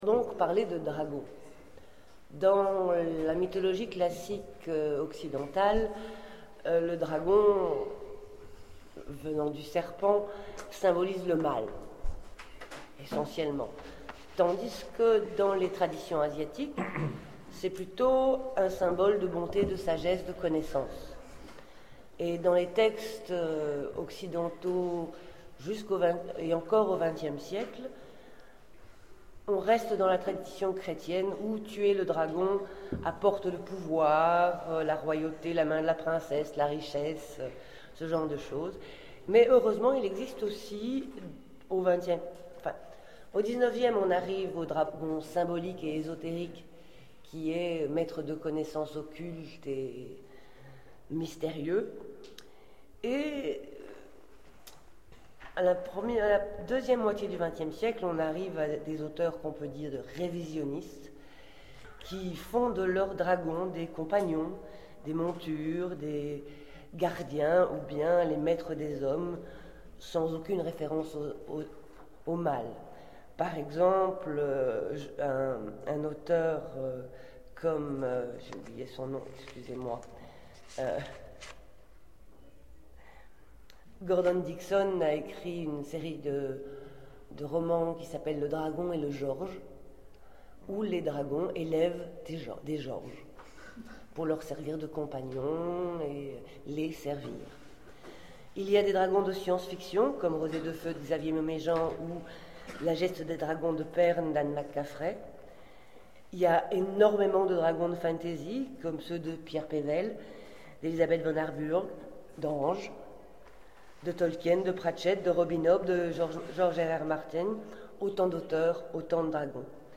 Oniriques 2013 : Conférence Mythologie du Dragon